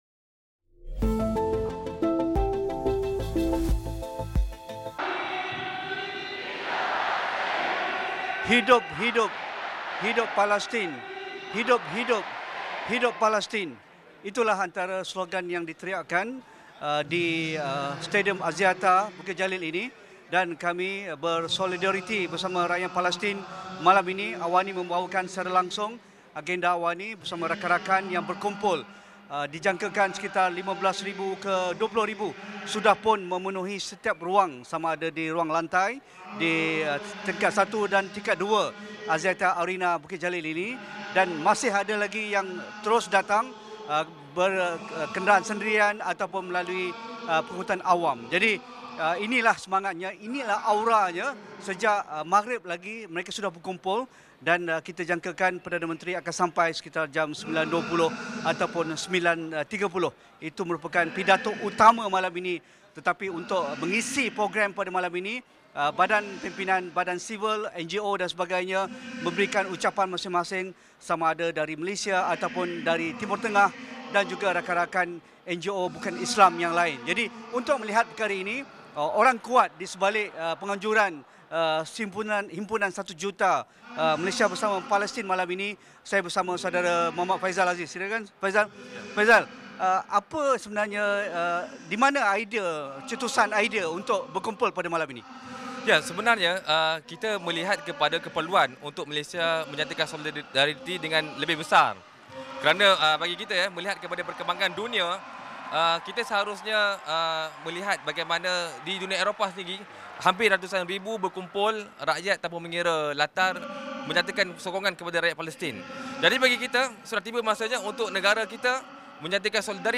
Ikuti Siaran Luar Astro AWANI bersempena Perhimpunan Malaysia Bersama Palestin di Stadium Axiata Arena, Bukit Jalil bermula 7.45 malam ini.